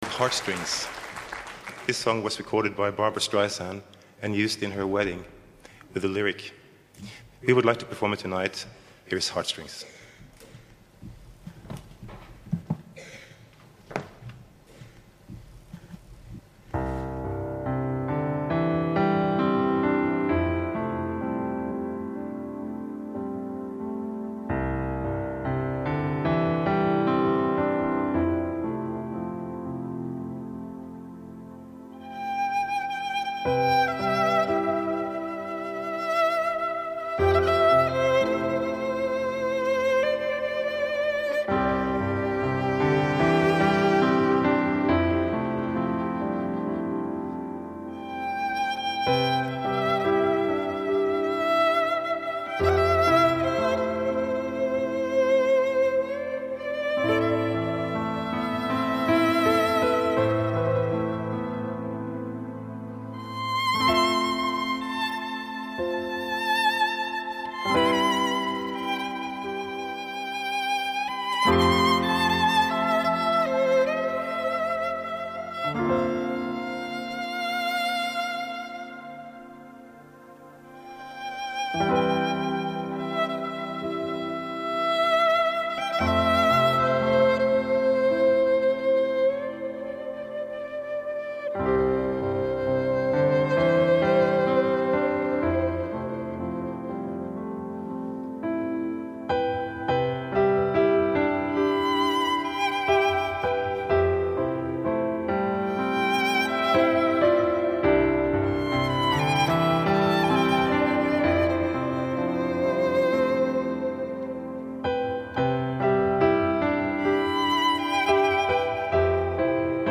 享誉国际的挪威双人音乐组合极品精选大碟
藉由键盘合成器、小提琴、弦乐，悠然漫步于流行、古典、新世纪、电影音乐的跨界空间
live